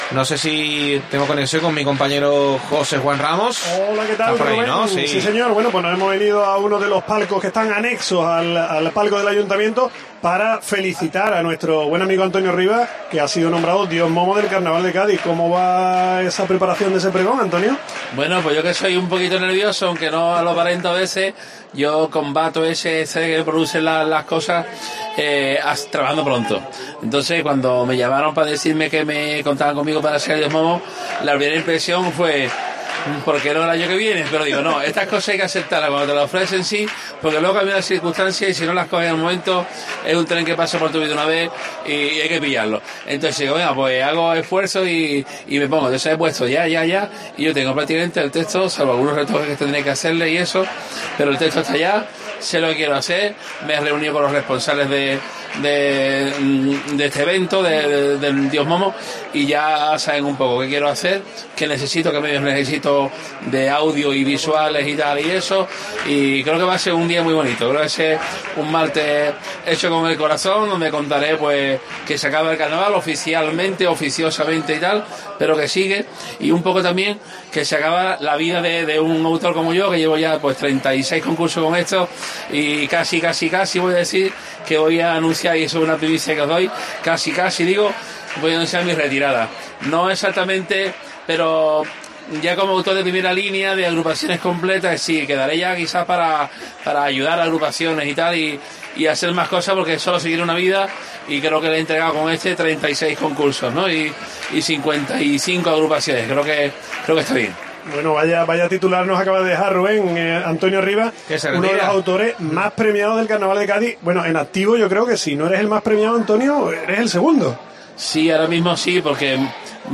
Entrevista
en el Gran Teatro Falla